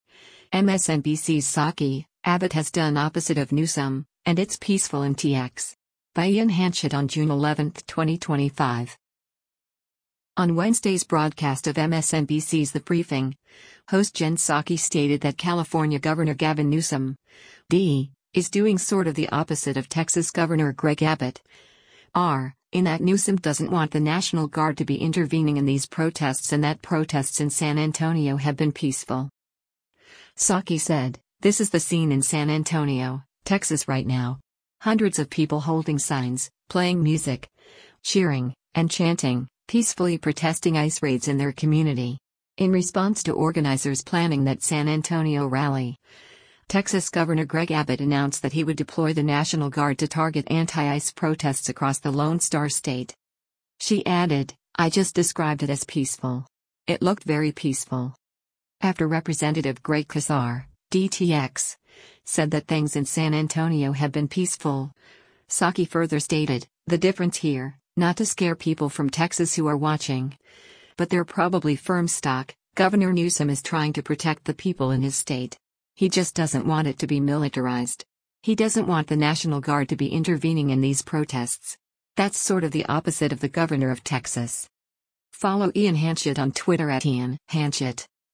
On Wednesday’s broadcast of MSNBC’s “The Briefing,” host Jen Psaki stated that California Gov. Gavin Newsom (D) is doing “sort of the opposite” of Texas Gov. Greg Abbott (R) in that Newsom “doesn’t want the National Guard to be intervening in these protests” and that protests in San Antonio have been peaceful.